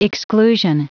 Prononciation du mot exclusion en anglais (fichier audio)
Prononciation du mot : exclusion